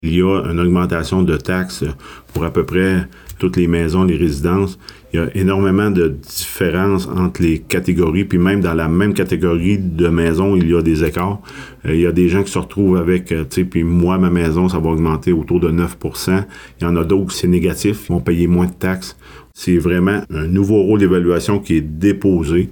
Certains verront le montant augmenter, d’autres diminuer, comme l’avait expliqué le maire de Fermont, Martin St-Laurent, dans la présentation du budget 2025 :